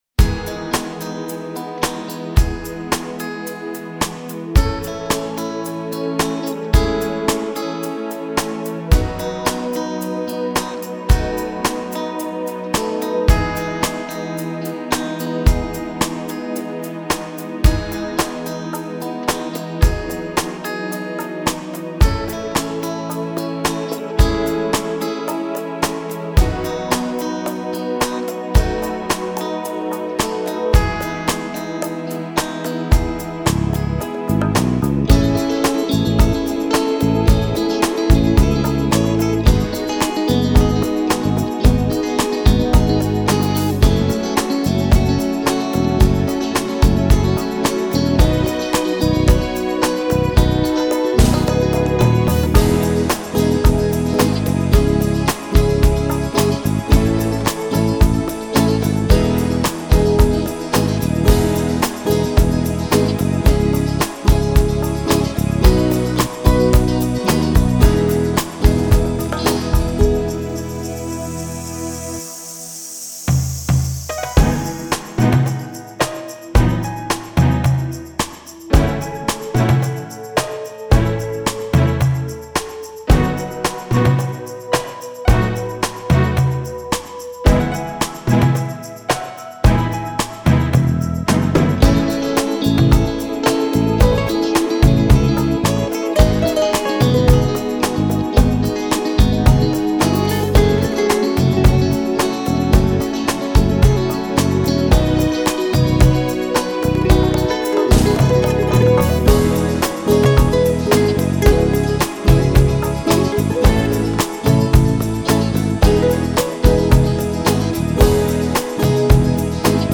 Mahr-EDV-Song-instrumental.mp3